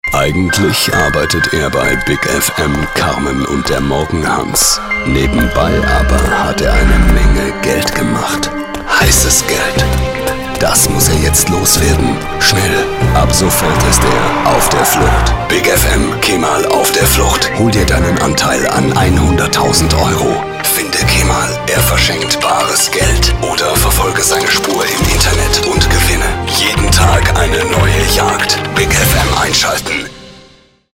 deutscher Sprecher und Sänger mit variantenreicher Stimme.
Sprechprobe: Sonstiges (Muttersprache):